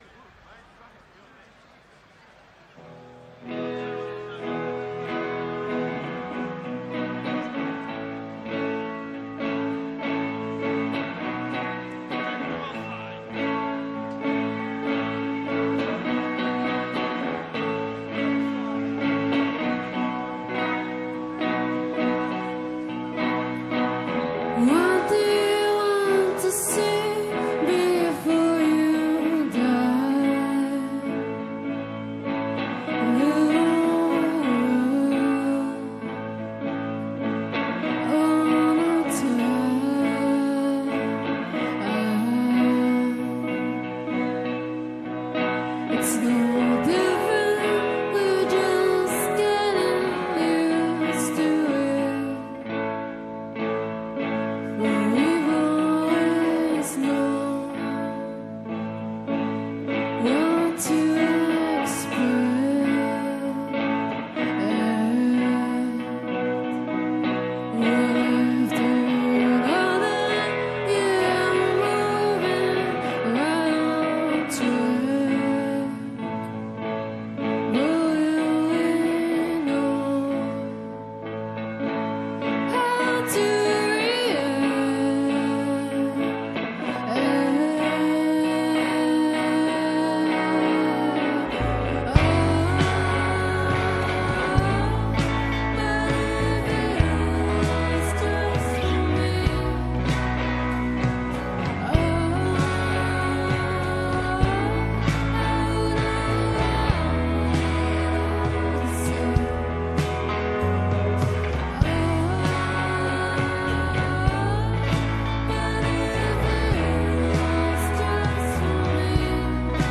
in concert at Best Kept Secret 2017